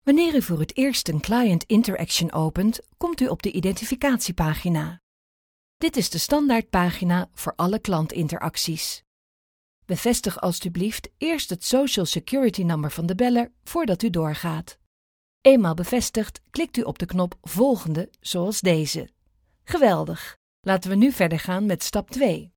Dutch female voice over
Sprechprobe: eLearning (Muttersprache):